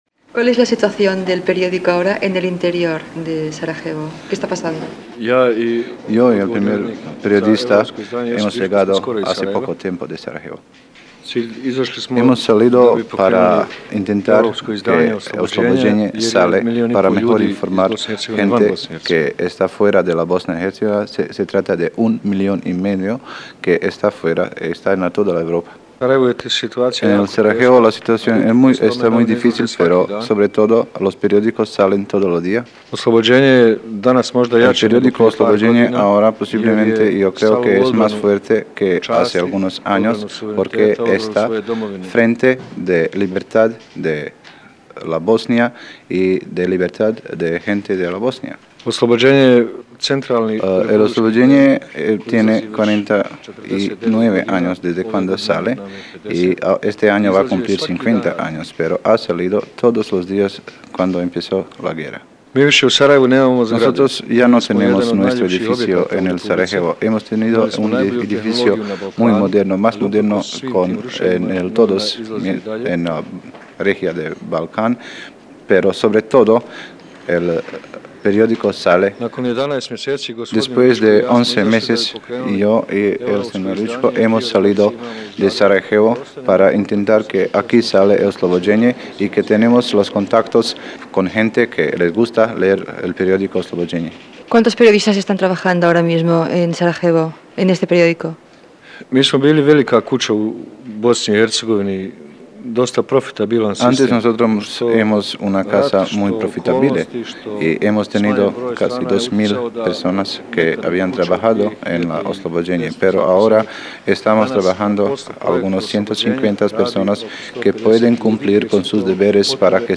Al pas de la tarda: reportatge des de Sarajevo - Ràdio 4, 1993